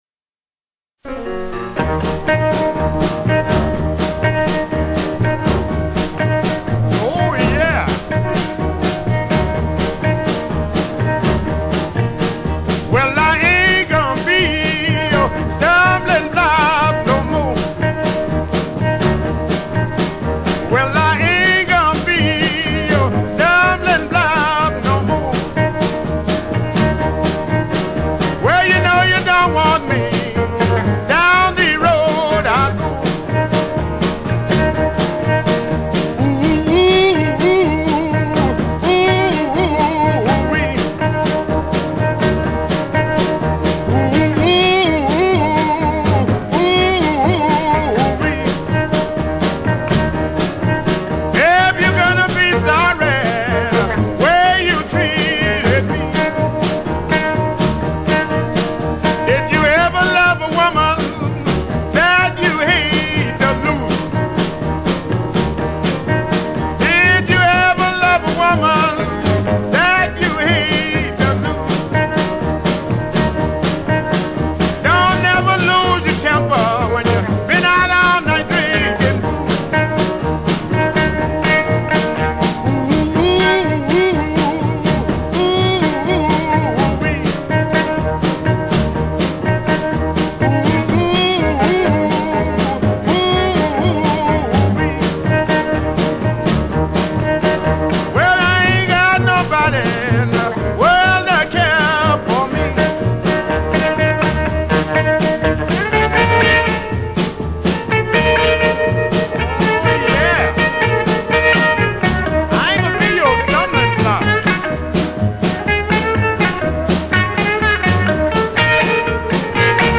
piano
tenor sax
guitar
bass fiddle
drums